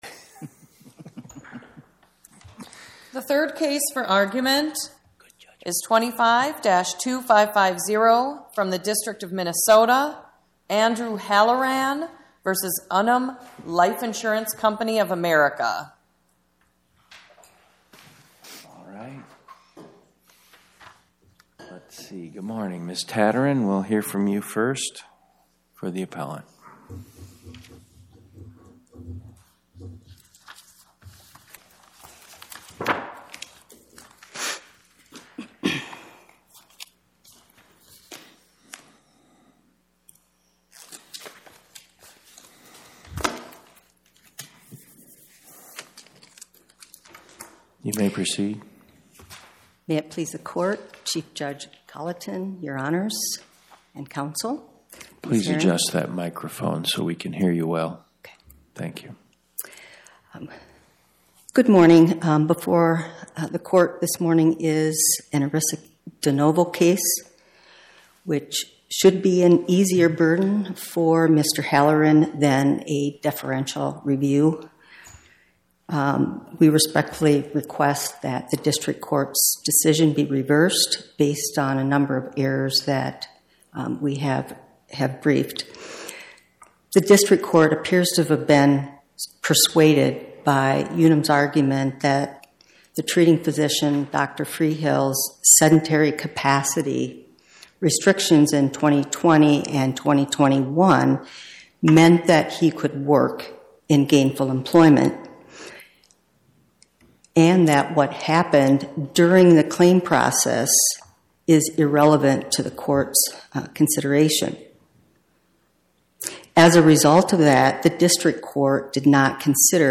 Co. of America Podcast: Oral Arguments from the Eighth Circuit U.S. Court of Appeals Published On: Wed Mar 18 2026 Description: Oral argument argued before the Eighth Circuit U.S. Court of Appeals on or about 03/18/2026